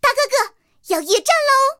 T-127夜战语音.OGG